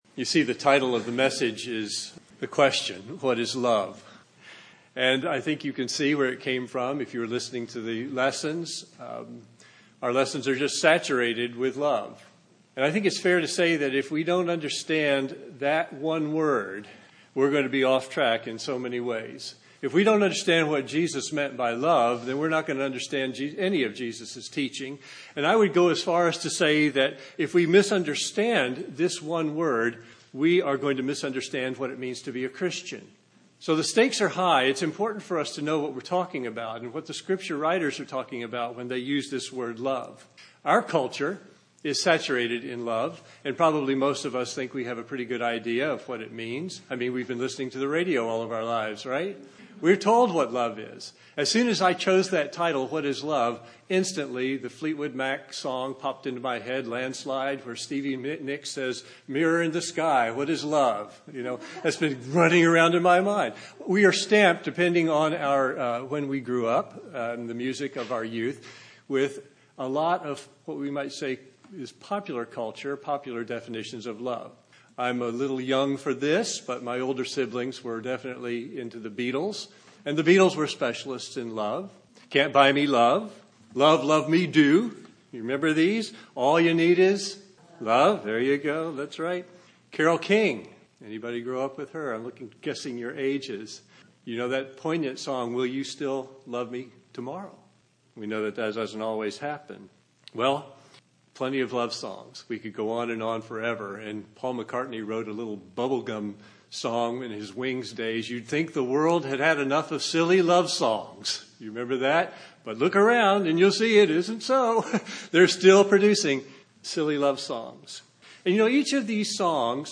I have posted Sunday’s sermon.